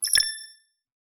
Futuristic Sounds (31).wav